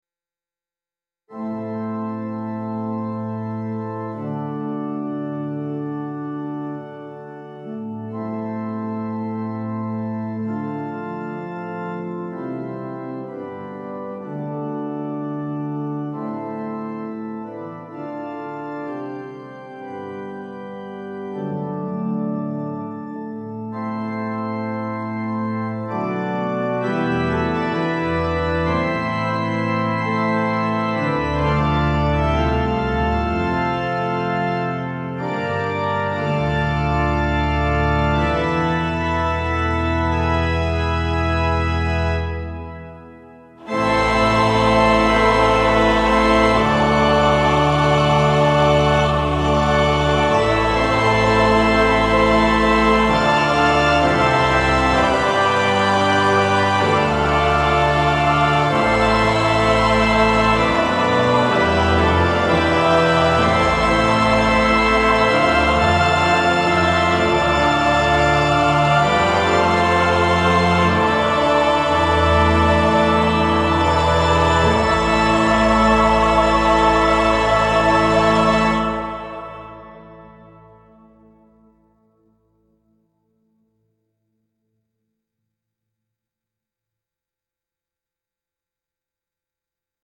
noble brass choir with sustained organ chords and gentle resolution